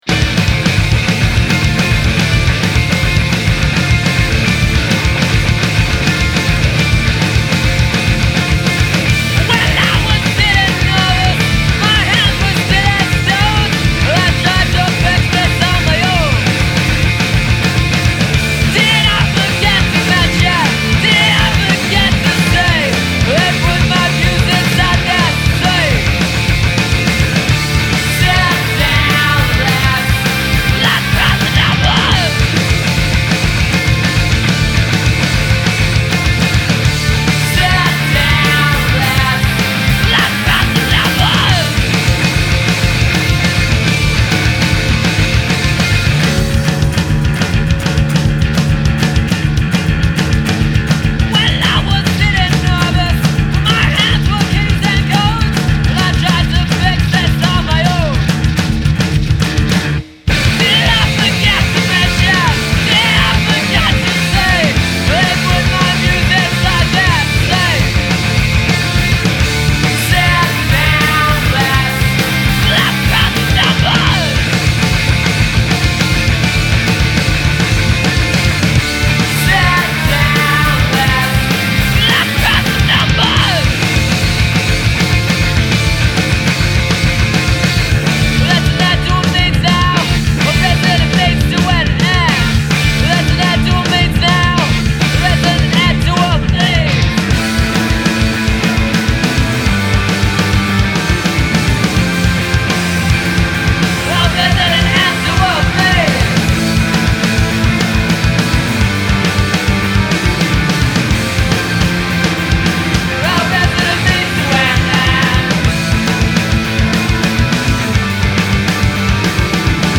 Unrelenting. Aggressive. Engrossing.